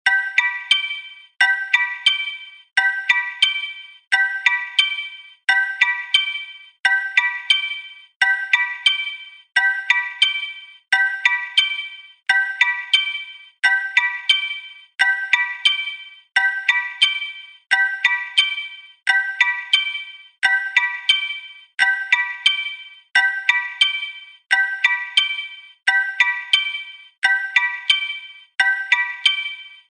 木琴風目覚まし時計のアラーム音。